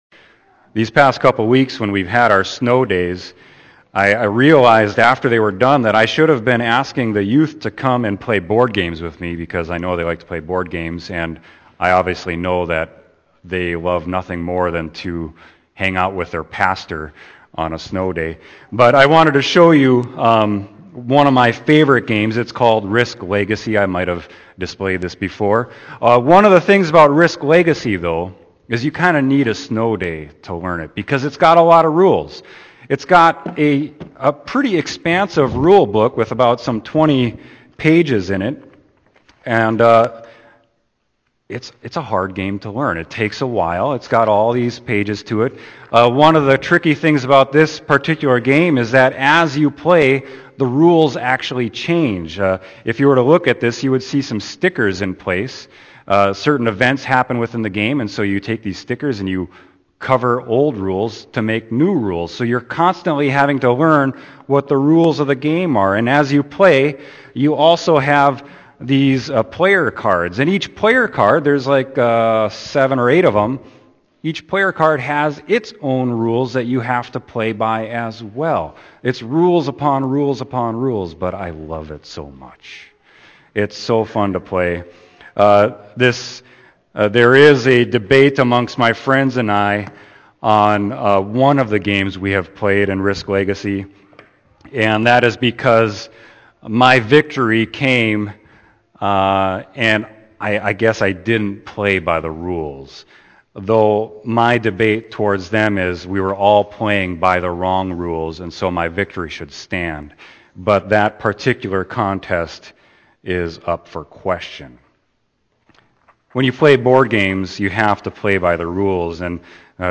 Sermon: Matthew 1.18-25